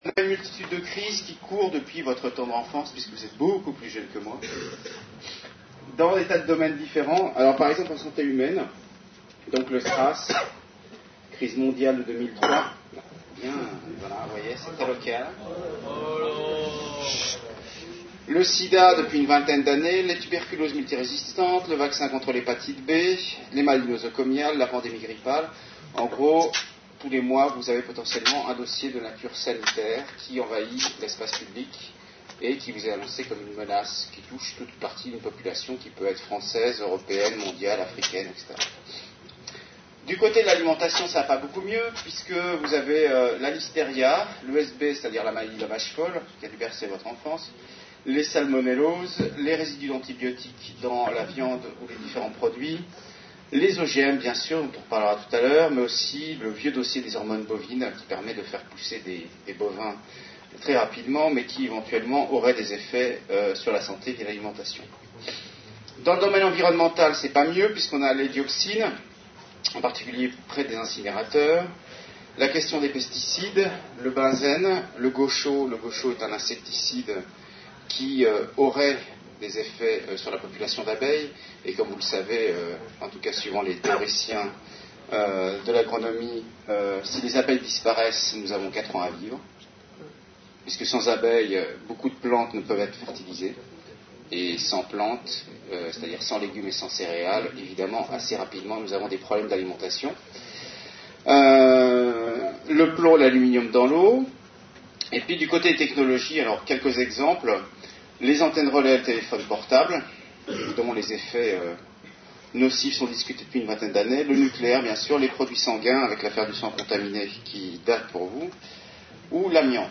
Une conférence de l'UTLS au lycée .